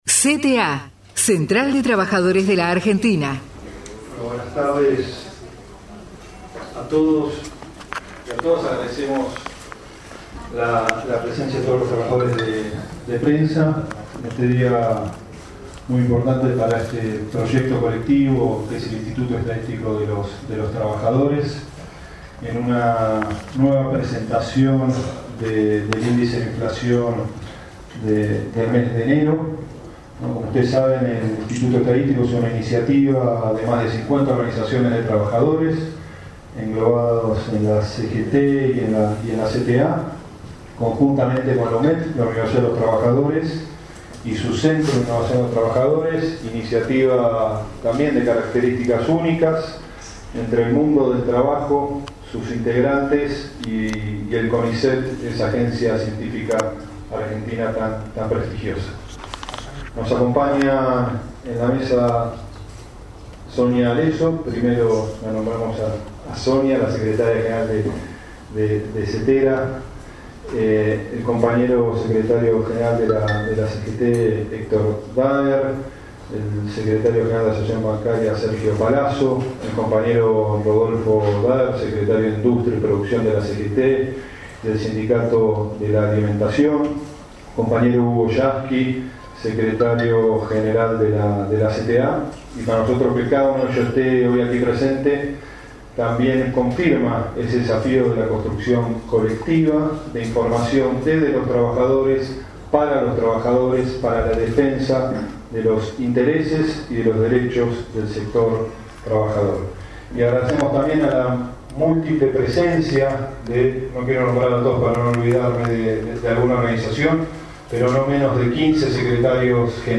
Presentación del nuevo informe de precios y salarios del Instituto Estadístico de los Trabajadores (IET) en la CGT Azopardo.